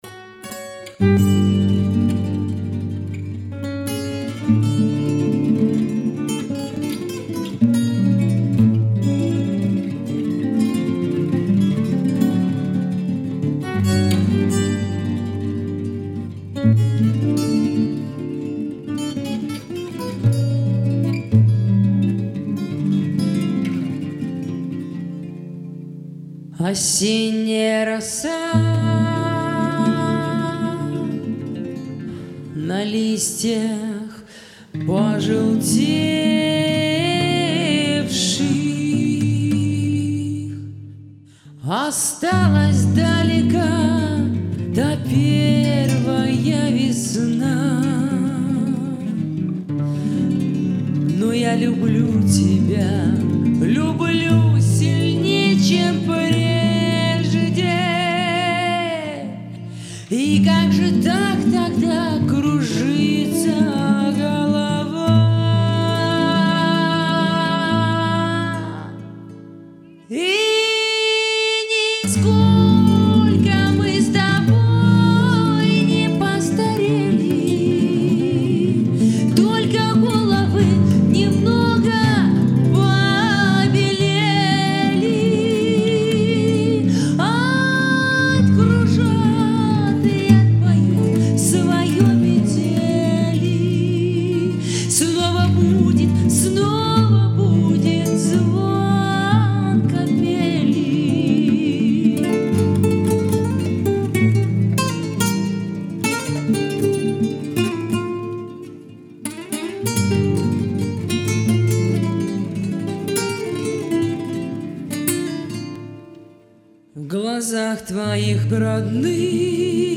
Романсы
Вокал и муз.сопровождение великолепные.